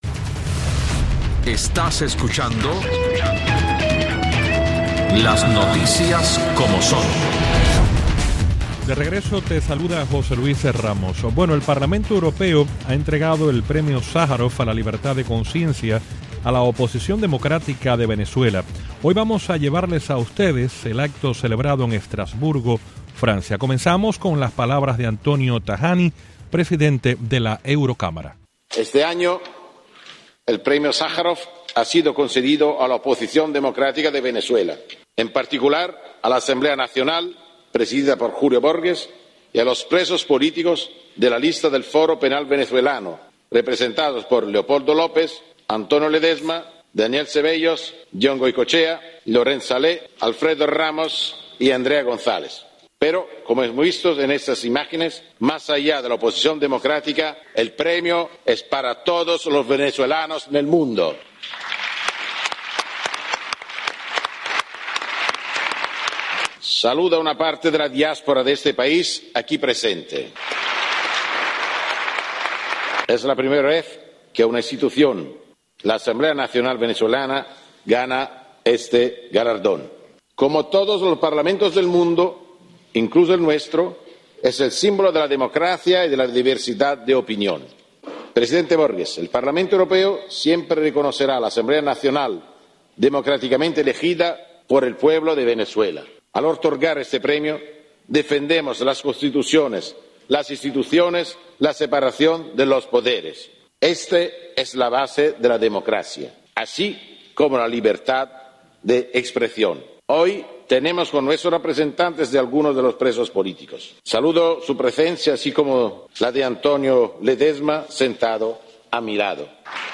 El Parlamento Europeo entrego el Premio Sájarov a la Libertad de Conciencia a la oposición democrática de Venezuela. Hoy llevamos a ustedes el acto celebrado en Estrasburgo, Francia.